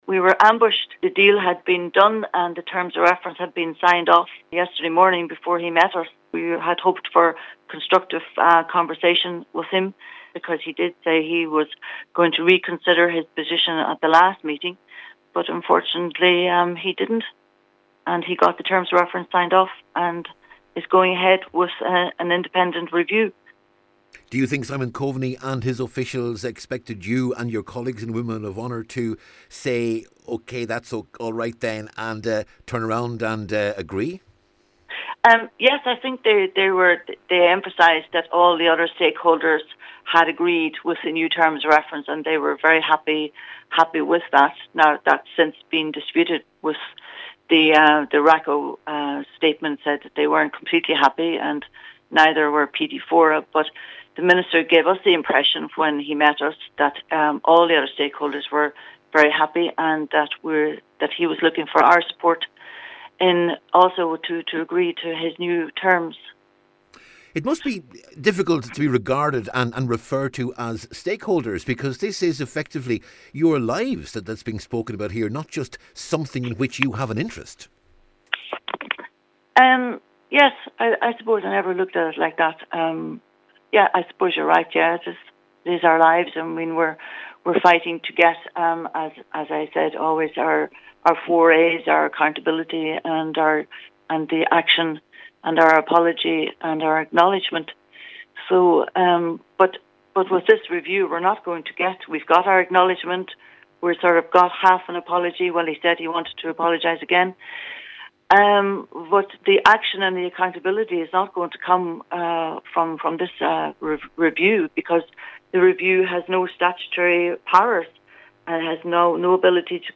she told Highland Radio News today the Women of Honour members were effectively ambushed…………..